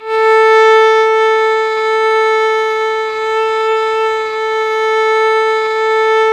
Index of /90_sSampleCDs/Roland - String Master Series/STR_Violin 4 nv/STR_Vln4 % marc